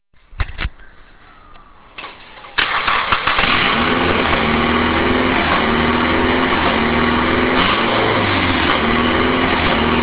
Ok plug the ignitor pack in and turn key (remember this is 8:15am Sunday morning and there is no exhaust on the motor bar a tiny dump pipe) and vrooooooooommmm well a little vrooooom and more farting really (listen to sound file).
car_start.wav